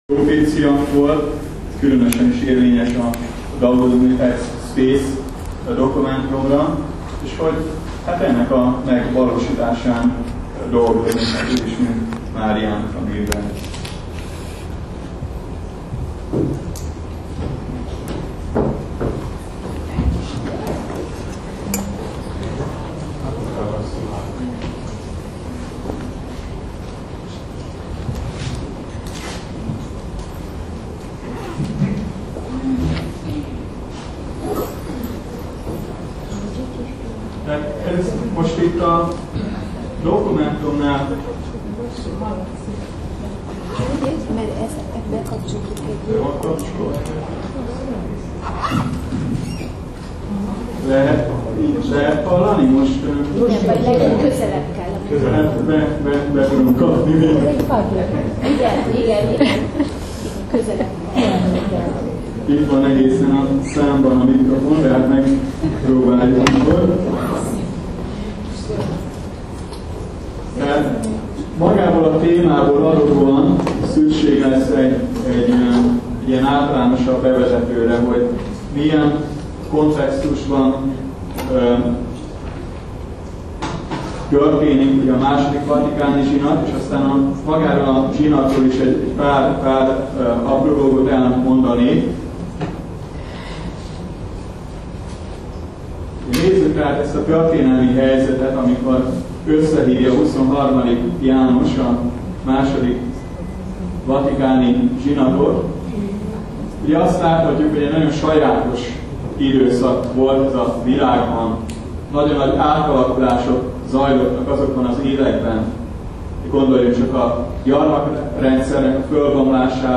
Előadás hanganyaga